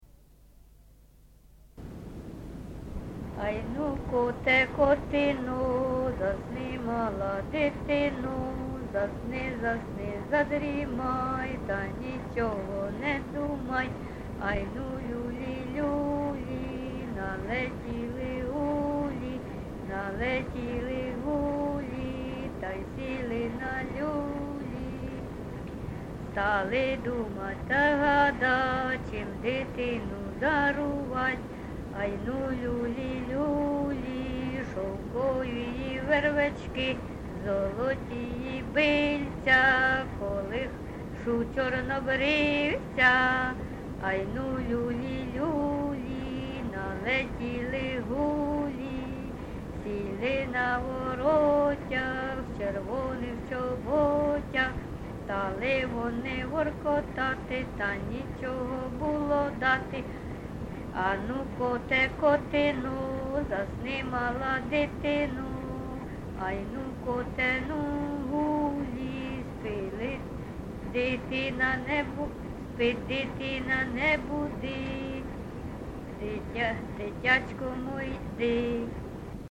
Місце записус. Харківці, Миргородський (Лохвицький) район, Полтавська обл., Україна, Полтавщина